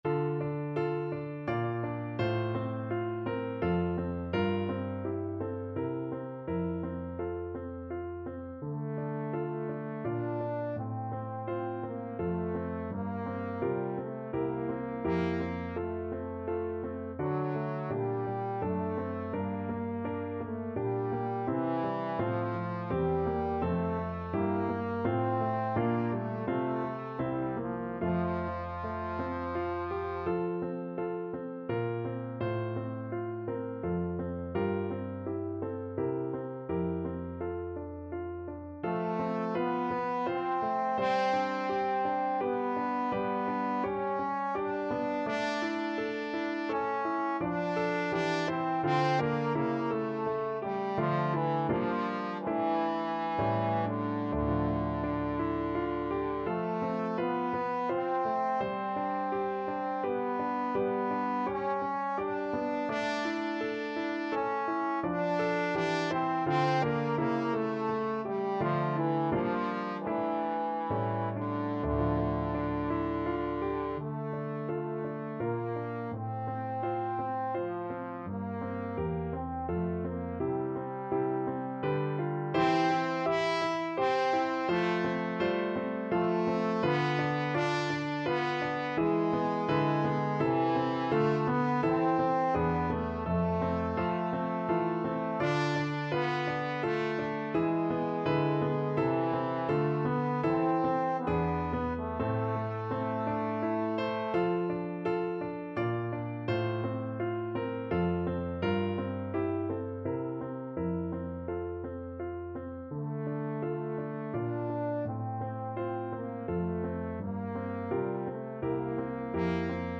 Trombone
Italian Baroque composer.
D minor (Sounding Pitch) (View more D minor Music for Trombone )
3/4 (View more 3/4 Music)
Allegretto grazioso = 84
Classical (View more Classical Trombone Music)